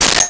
Sword Hit 04
Sword Hit 04.wav